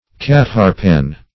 Cat-harpin \Cat"-harp`in\, n.
cat-harpin.mp3